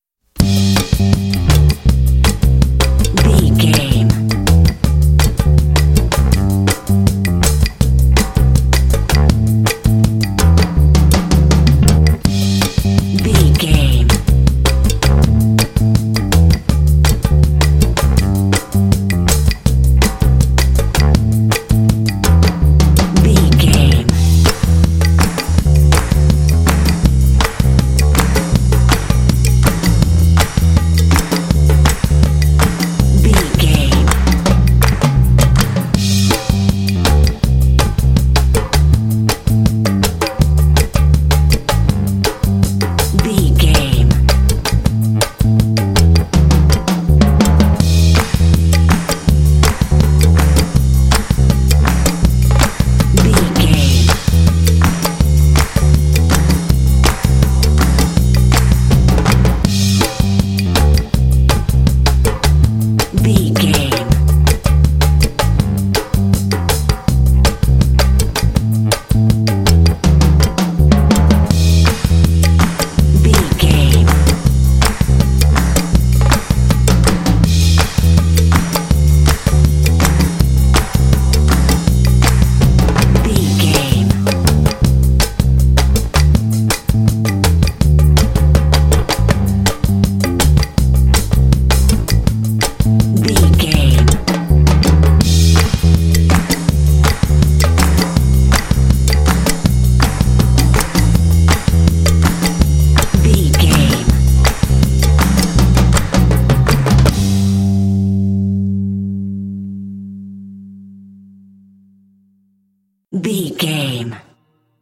This fun and upbeat track has an energetic Latin groove.
Uplifting
Ionian/Major
cheerful/happy
groovy
driving
bass guitar
percussion
drums
latin jazz